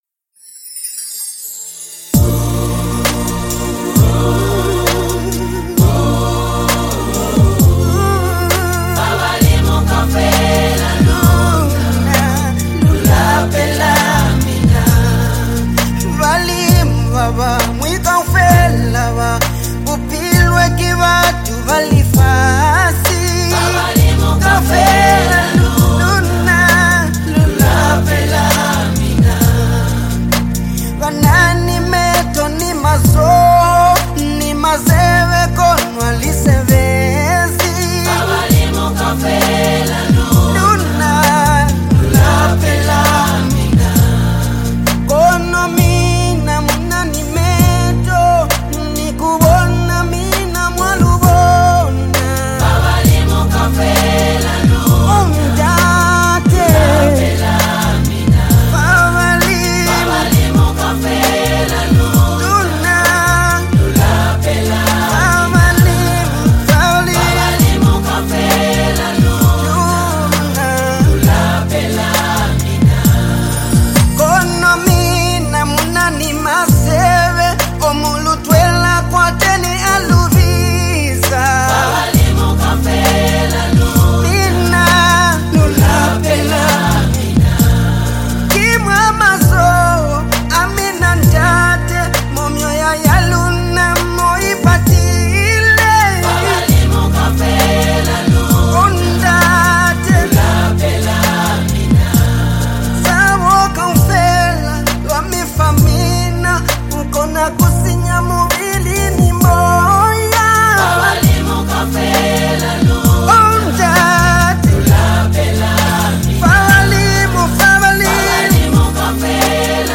gospel
With soul-stirring vocals and a deeply spiritual message
With a beautifully composed melody and rich instrumentation